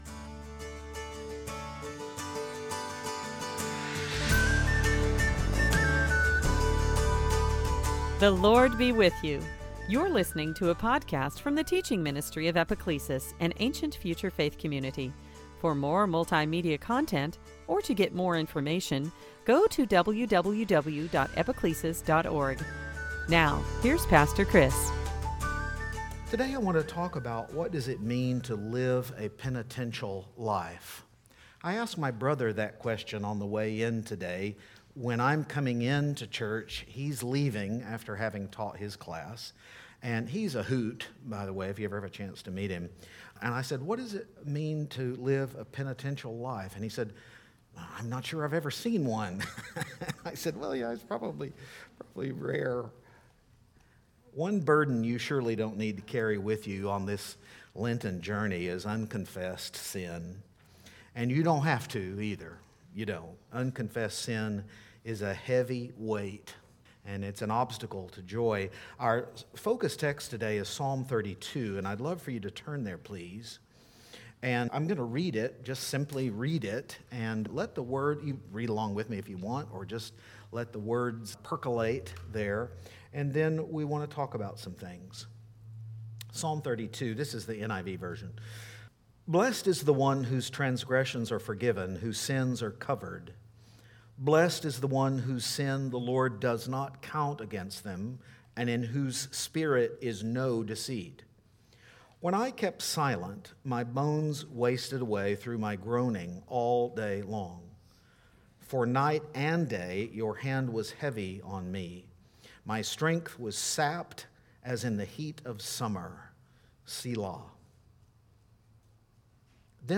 Psalm 32 Service Type: Lent What does it mean to live a penitent life?